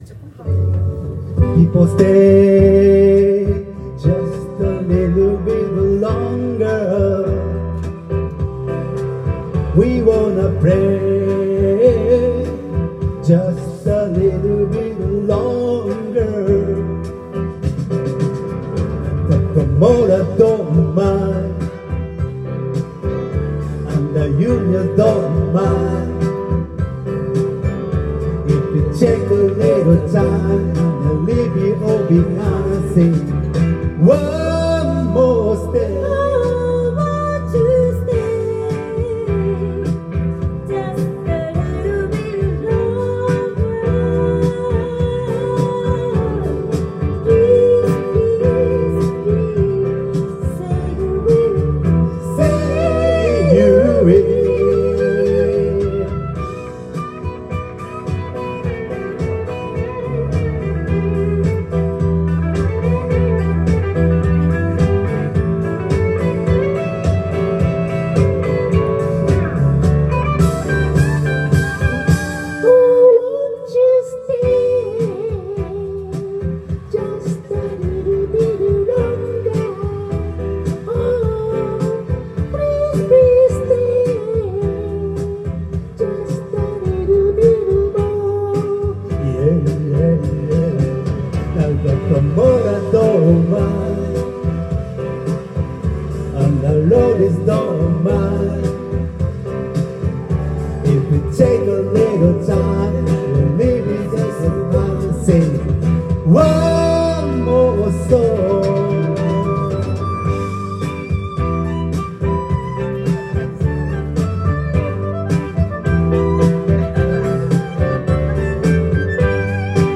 Duet & Chorus Night Vol. 22 TURN TABLE